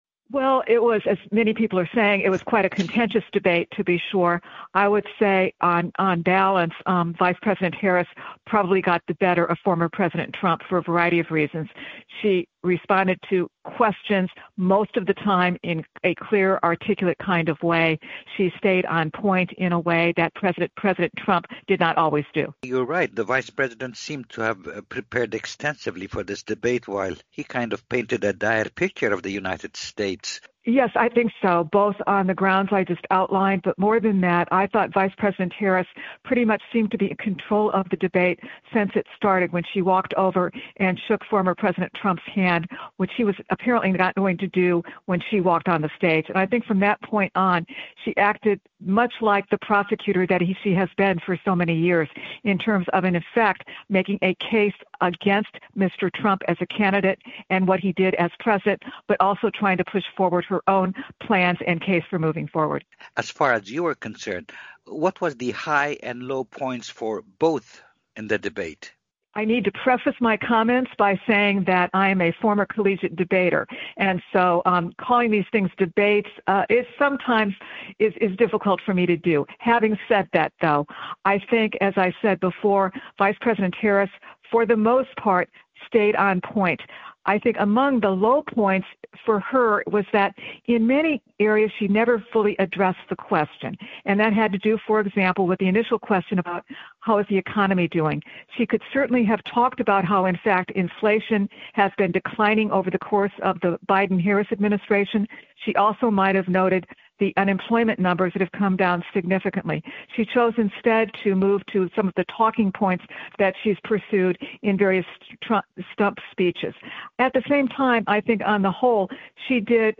Analyst speaks on presidential debate performance